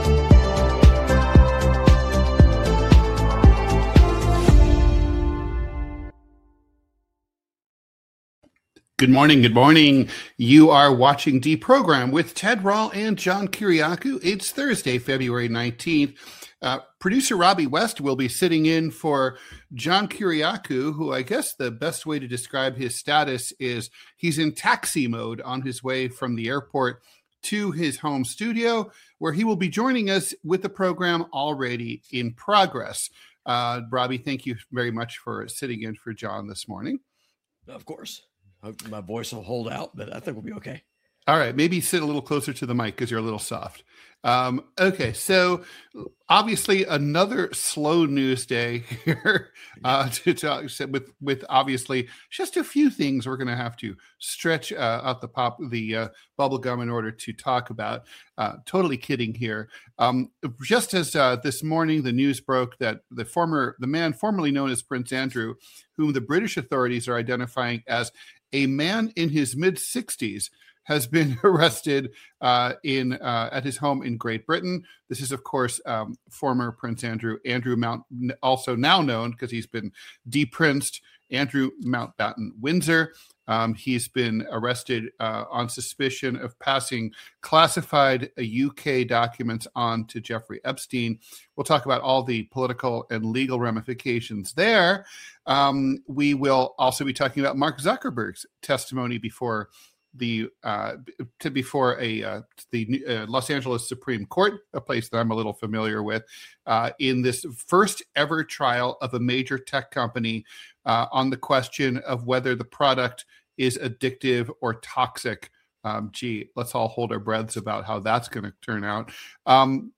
Political cartoonist Ted Rall and CIA whistleblower John Kiriakou deprogram you from mainstream media every weekday at 9 AM EST.